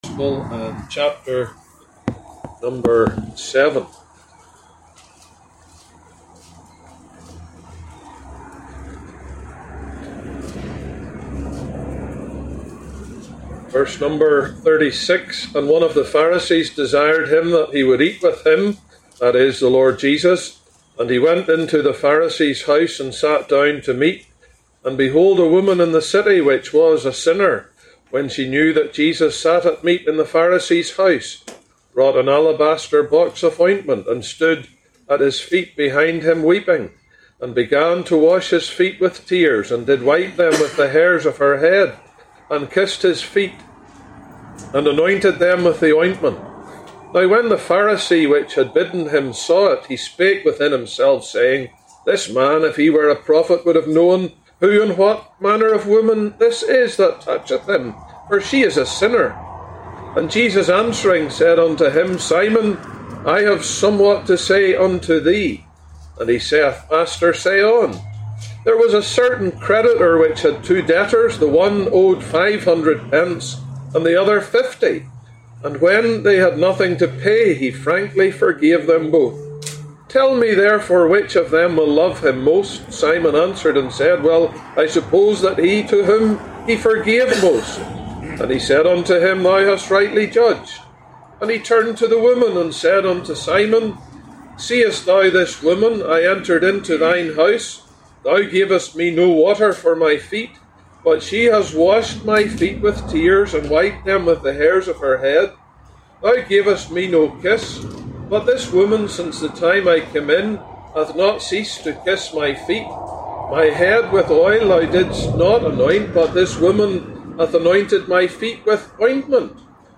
2025 Gospel Tent